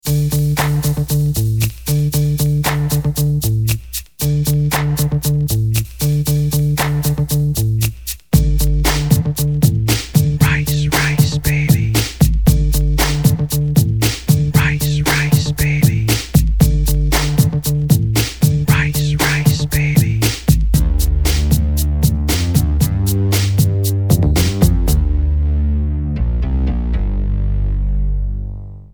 A short parody